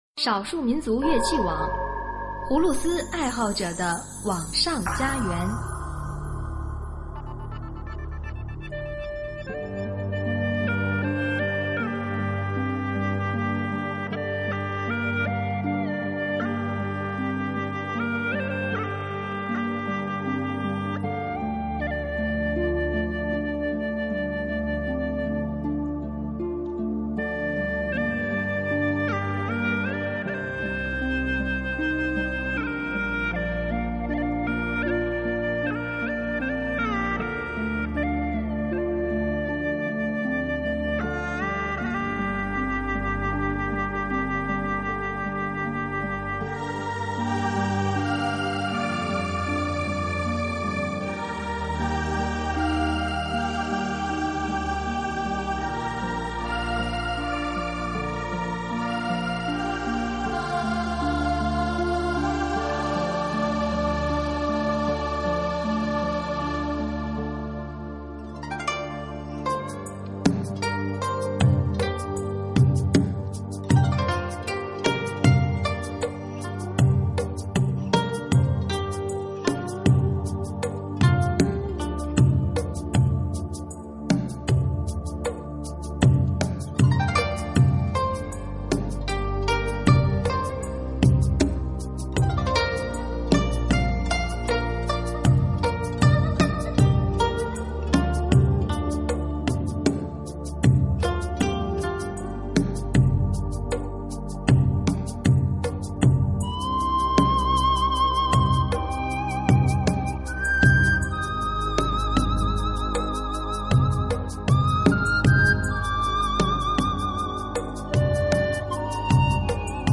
可惜葫芦丝部分少了一些
就是喜欢葫芦丝的悠扬，感觉非常的轻松。
深沉委婉,优美动听!好曲子!谢谢了!编曲相当好,排箫\鼓点的效果很不错.
不过，里面的高音，似乎笛子才能吹得出来。